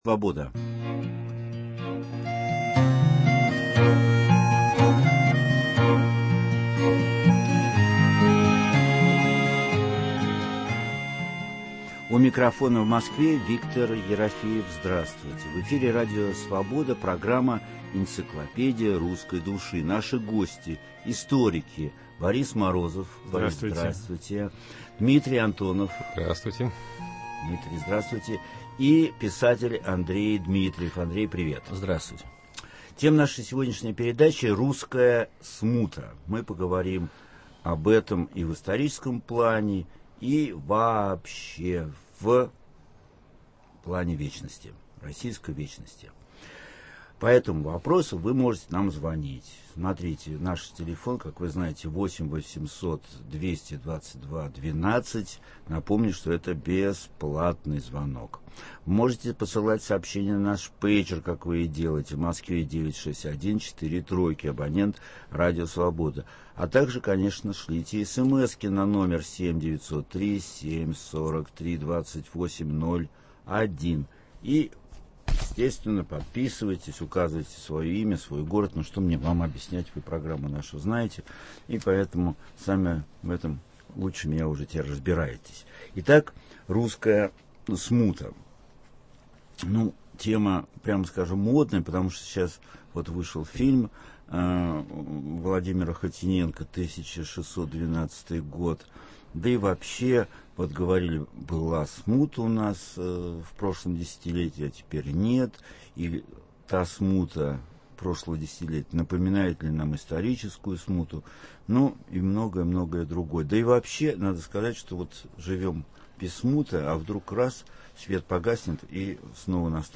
Мы поговорим о Смутном времени в истории России. Наши гости - историки